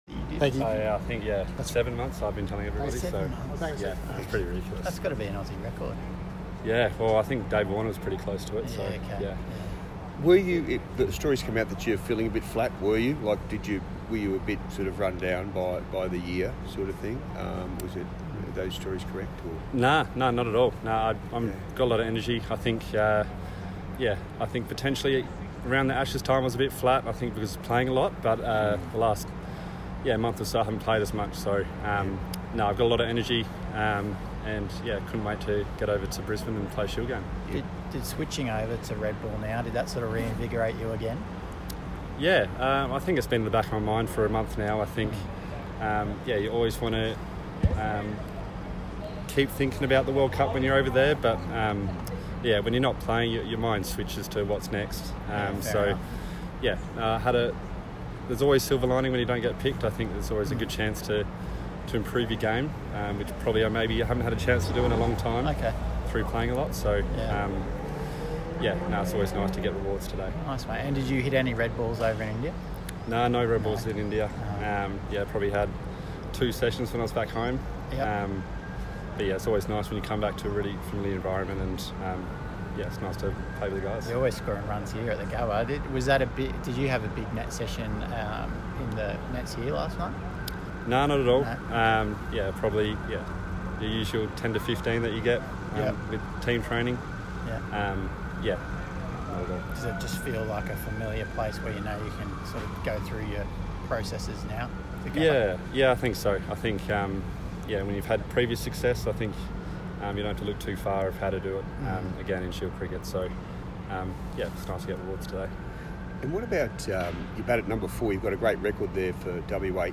WA all rounder Cam Green (96) speaking after play on day 3 of the Shield game at the Gabba; WA lead by 56. Gurinder Sandhu (5-77) (QLD) spoke on the livestream after play.